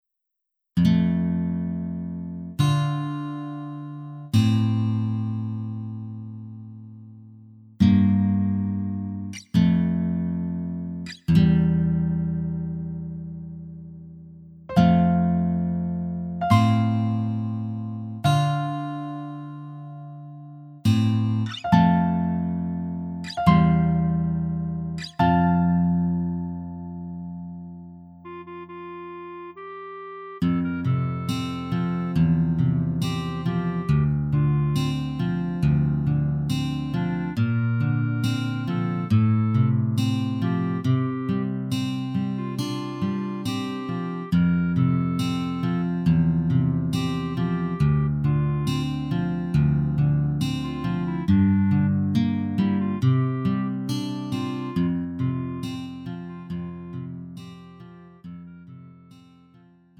음정 -1키 3:35
장르 가요 구분